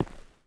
Terrarum/assets/mods/basegame/audio/effects/mining/ROCK.7.wav at 5da4cee22e2ca73e6cd1a6cb7ef122ce182c280d
ROCK.7.wav